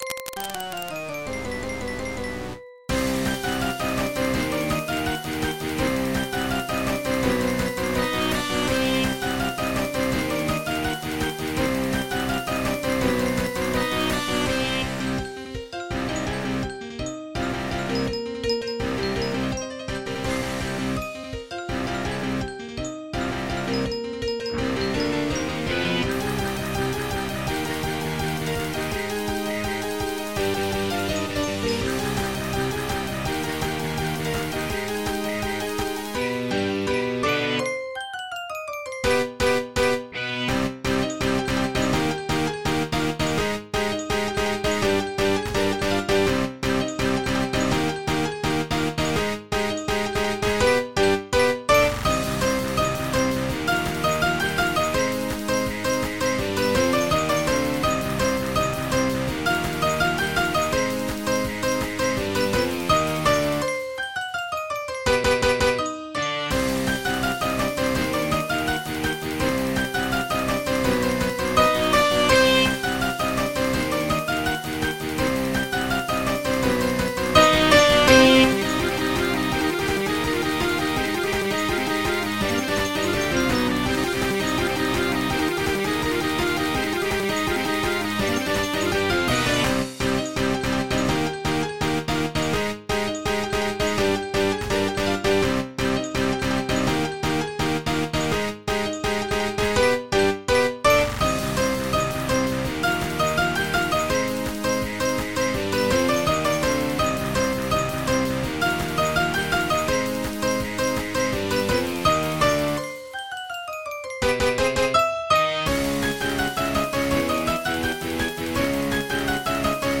MIDI 89.92 KB MP3